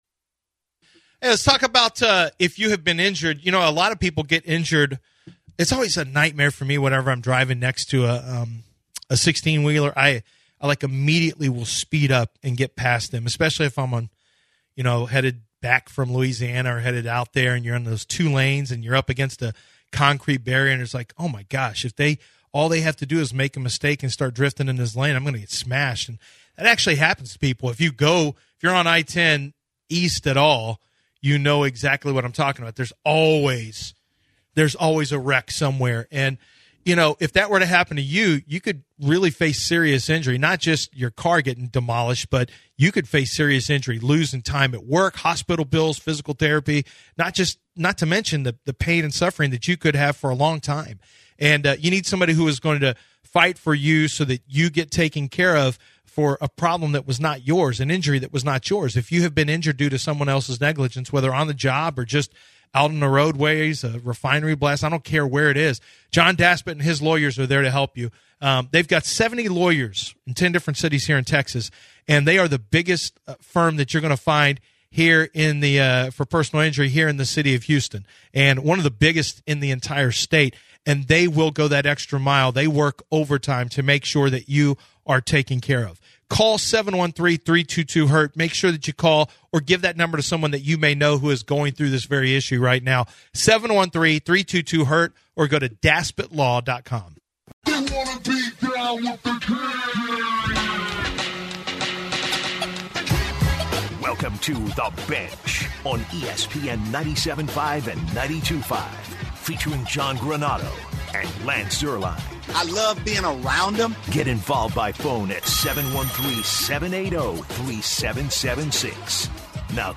this first hour covered The official Demeco Ryans hire and callers had their opinion on it and chimed in on the hire.